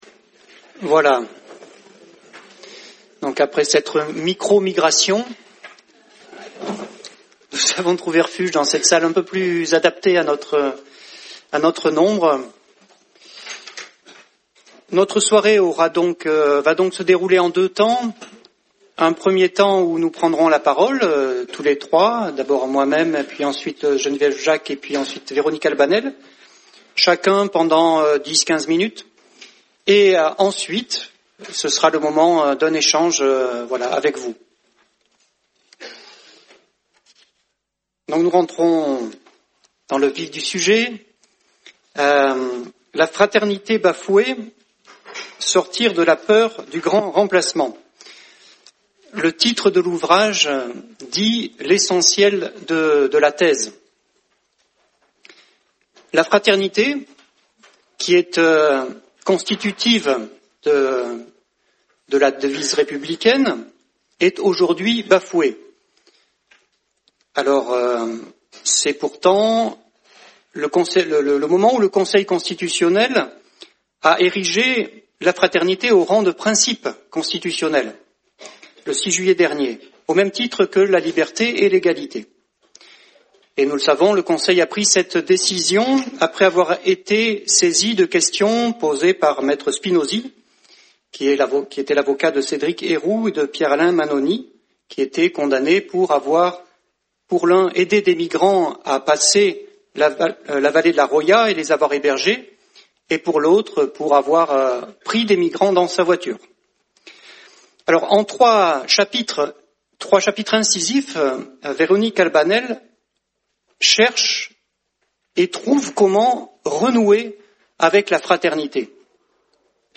Soirée-débat du 25 octobre 2018